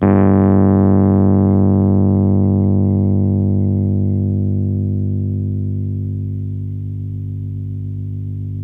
RHODES CL01R.wav